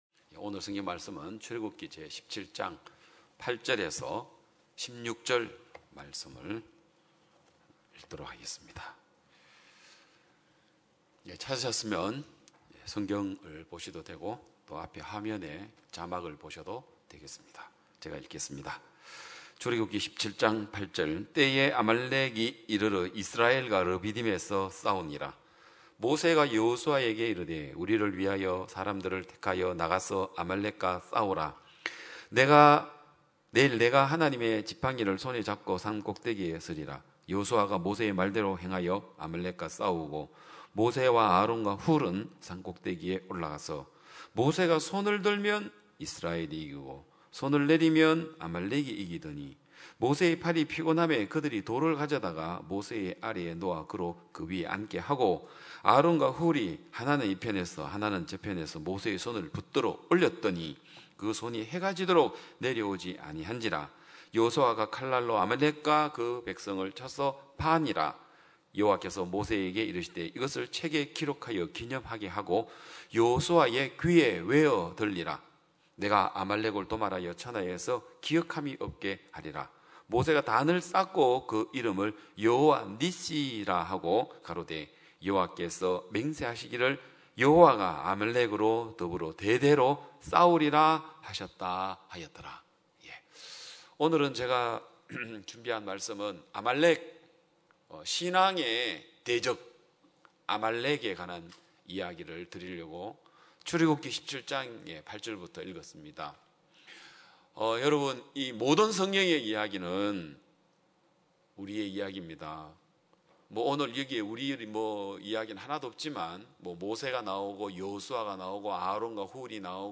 2022년 7월 3일 기쁜소식양천교회 주일오전예배
성도들이 모두 교회에 모여 말씀을 듣는 주일 예배의 설교는, 한 주간 우리 마음을 채웠던 생각을 내려두고 하나님의 말씀으로 가득 채우는 시간입니다.